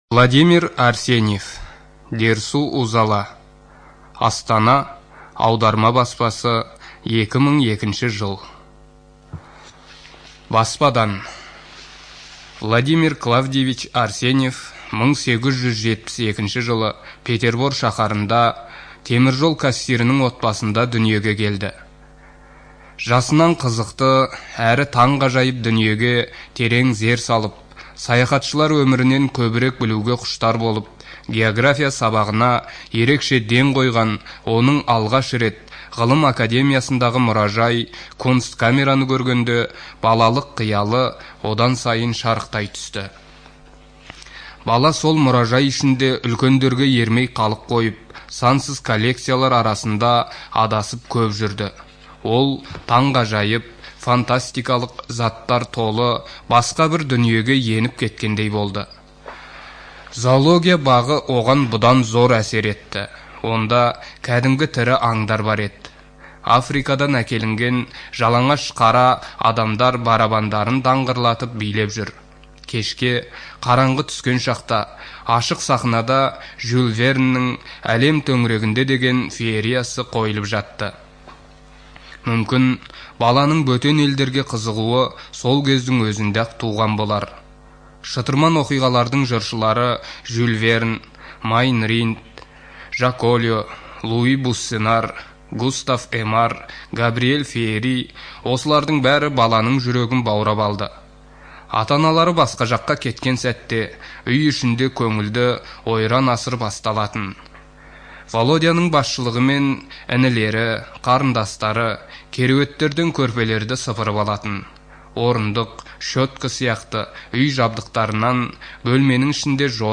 Студия звукозаписиКазахская республиканская библиотека для незрячих и слабовидящих граждан